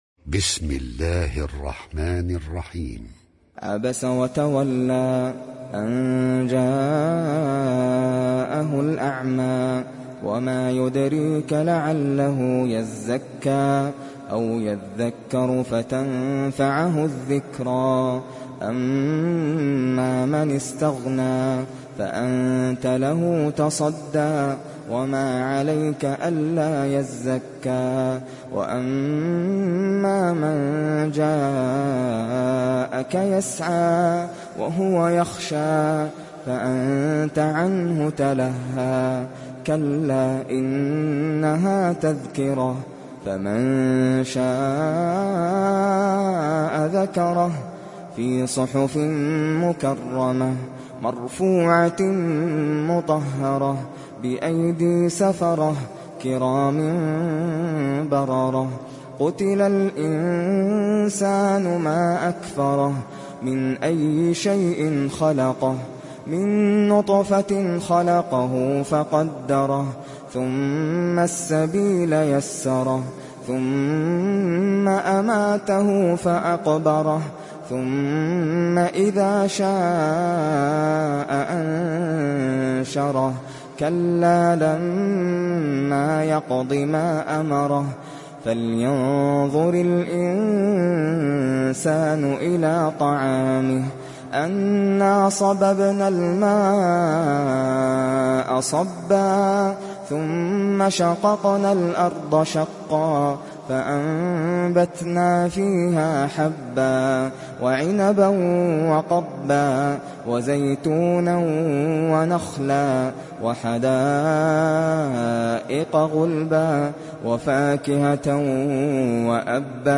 Abese Suresi İndir mp3 Nasser Al Qatami Riwayat Hafs an Asim, Kurani indirin ve mp3 tam doğrudan bağlantılar dinle